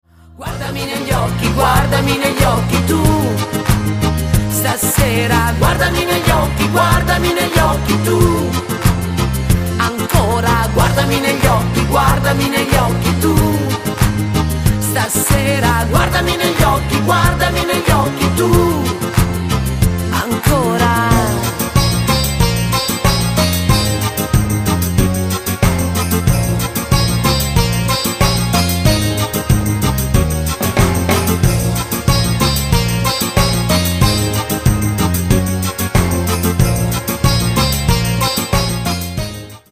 CUMBIA  (4.25)